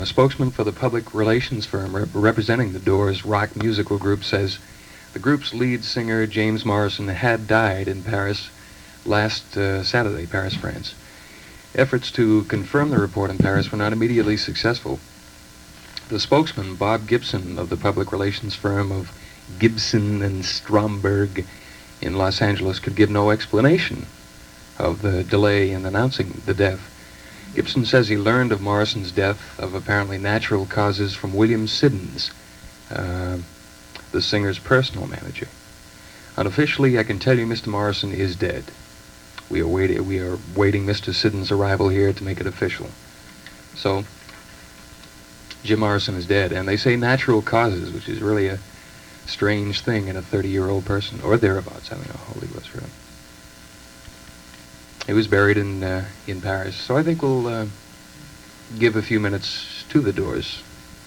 Jim-Morrison-death-announcement-1971.mp3